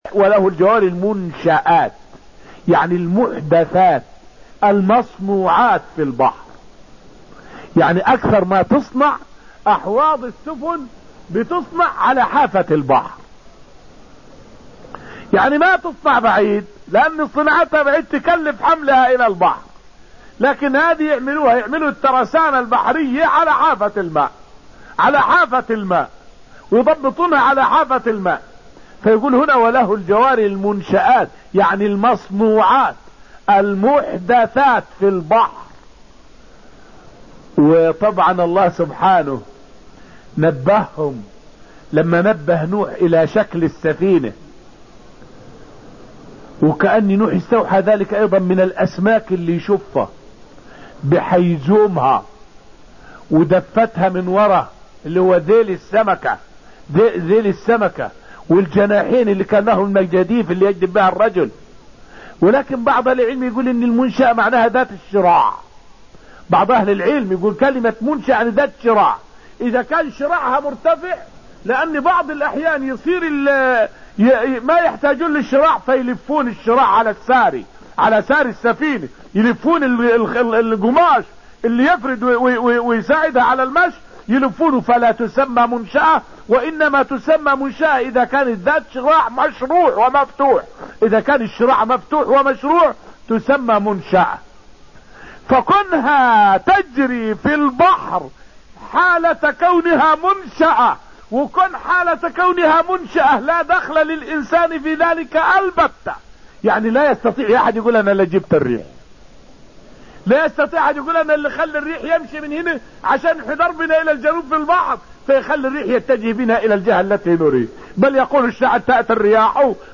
فائدة من الدرس الأول من دروس تفسير سورة الرحمن والتي ألقيت في المسجد النبوي الشريف حول أن أهل العلم والاتباع هم من يعرفون معجزات القرآن وليس الجهلة.